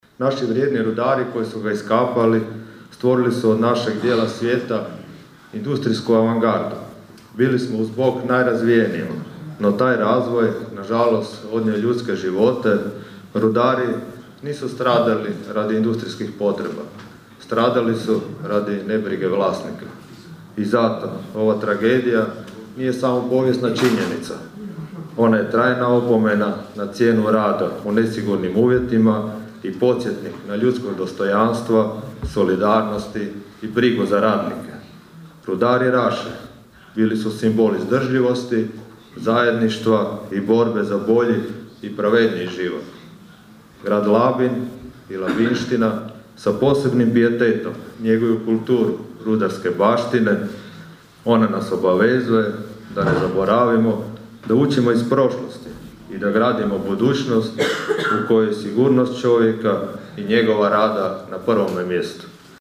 U Raši je održana komemoracija povodom 86. obljetnice rudarske tragedije iz 1940. godine u kojoj je poginulo 185 rudara.
Gradonačelnik Labina Donald Blašković posebno je istaknuo: (